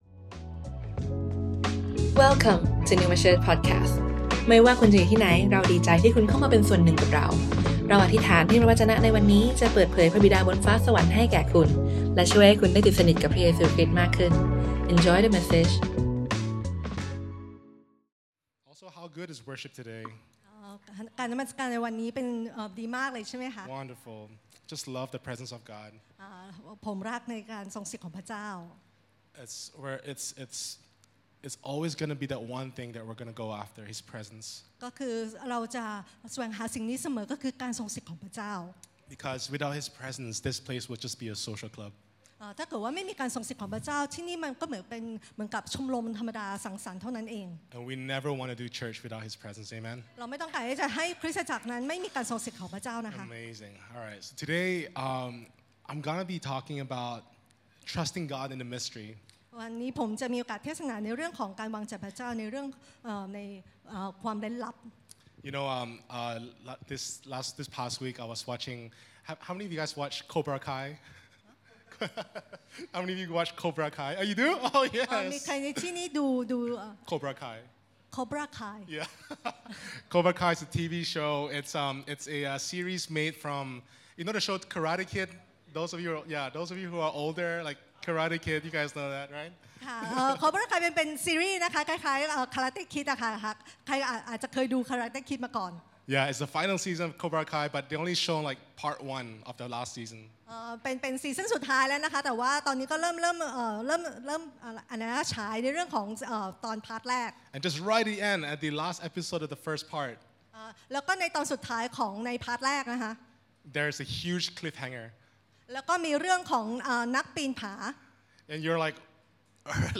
Originally recorded on Sunday 28th July 2024, at Neuma Bangkok.